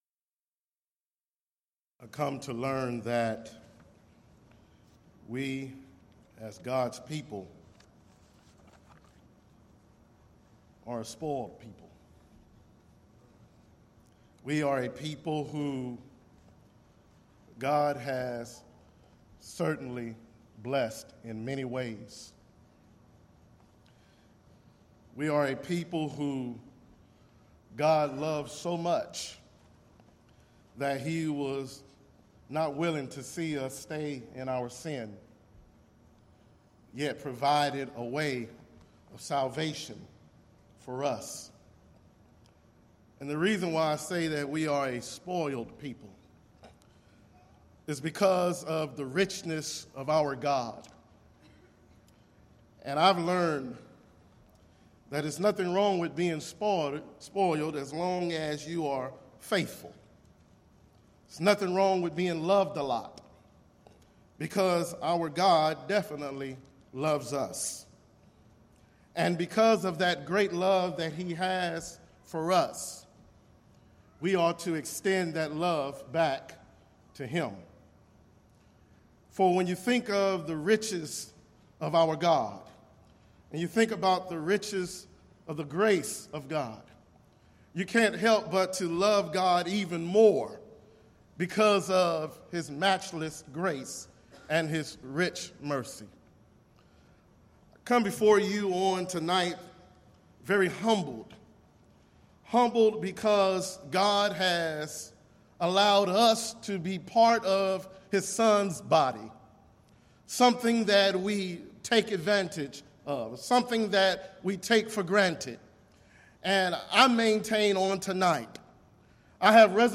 Event: 21st Annual Gulf Coast Lectures
lecture